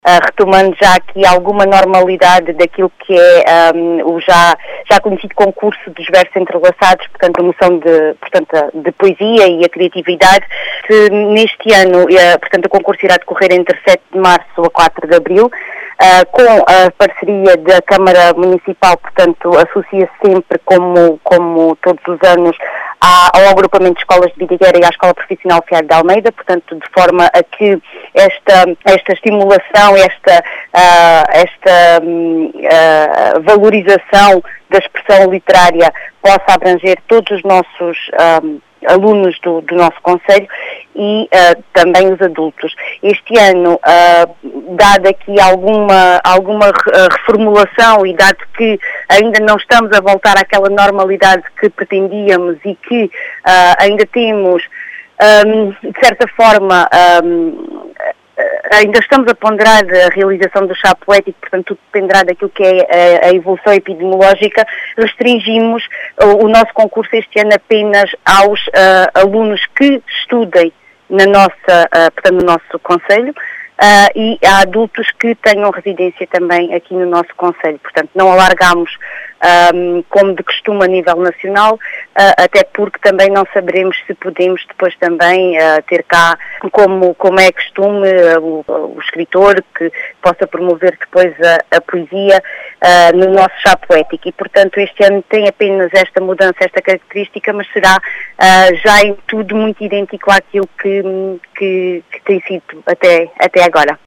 As explicações são de Ana Patricia Marreiros, vereadora da Câmara Municipal de Vidigueira.